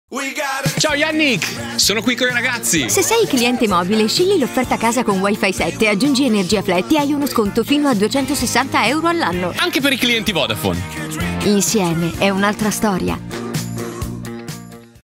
Come detto, in questi giorni è stato lanciato un nuovo spot pubblicitario di Fastweb, con protagonisti Jannik Sinner (testimonial di Fastweb) e Alessandro Cattelan (testimonial di Vodafone Italia).
In questo nuovo spot, come nella precedente versione, Jannik Sinner chiama Alessandro Cattelan, che risponde presentando tre ragazzi che rappresentano i servizi mobile, fisso ed energia.
Durante il video, mentre a schermo viene mostrata l’offerta Fastweb Casa Pro in sconto a 25,95 euro al mese per i clienti di rete mobile, la voce fuori campo racconta l’offerta convergente: “se sei cliente mobile scegli l’offerta Casa con Wi-Fi 7, aggiungi Energia Flat e hai uno sconto fino a 260 euro all’anno”.
In seguito, Cattelan ricorda che lo stesso meccanismo è disponibile anche per i clienti Vodafone, mentre alla fine si sente il claim “insieme è un’altra storia”.
La colonna sonora del video è la canzone originale “Me and You (We’ll Make Our Dreams Come True)”la stessa del primo spot istituzionale del corporate brand Fastweb + Vodafone.